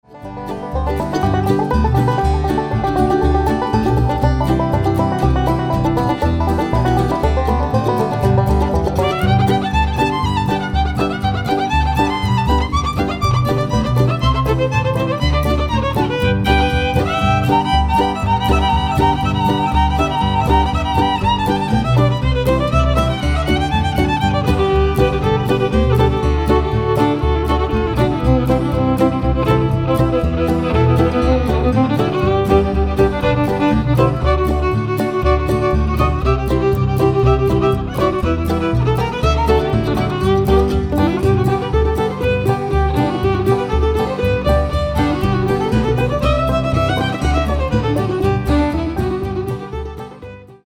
An instrumental album
mostly from the Bluegrass and Old-Time repertoire